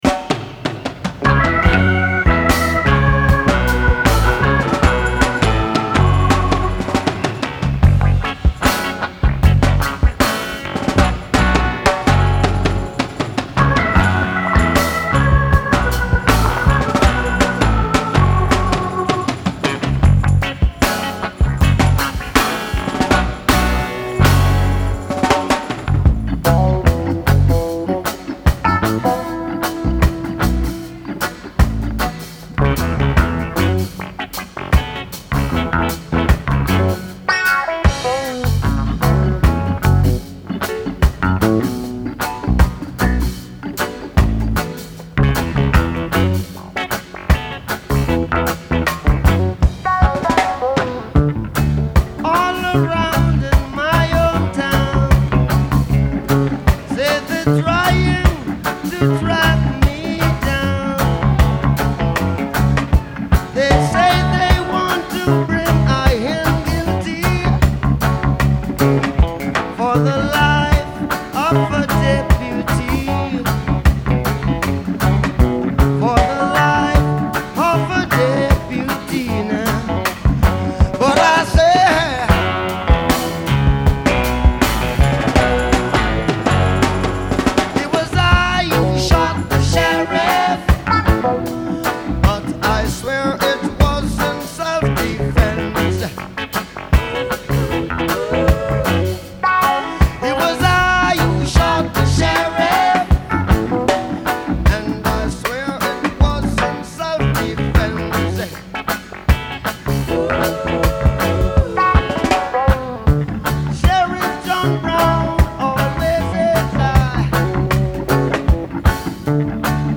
Genre : Reggae, Musiques du monde
Live At The Rainbow Theatre, London